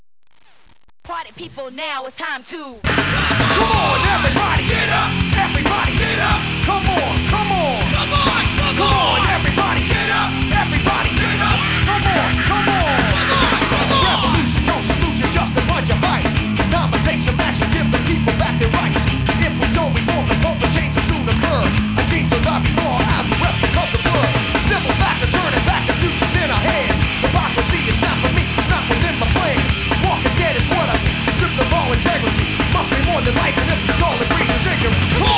Bass
Drums
Guitar
Vocals